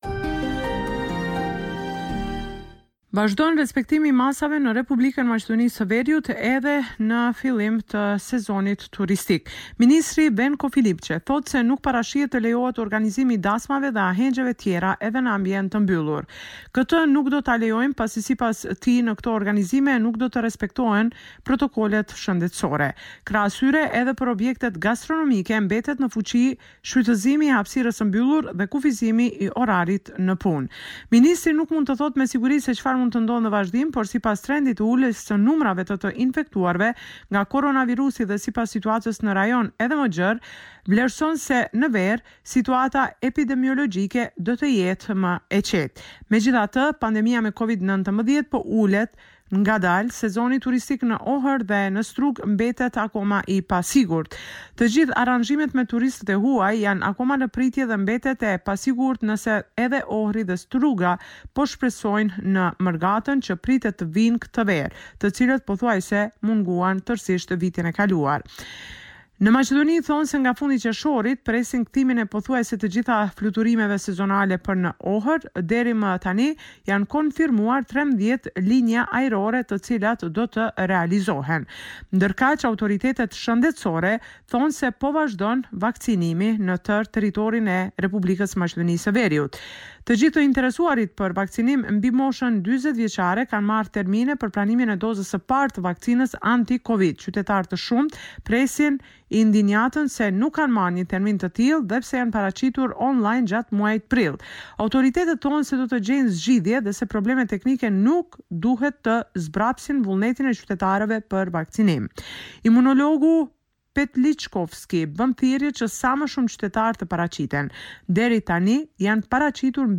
Raporti me te rejat me te fundit nga Maqedonia e Veriut.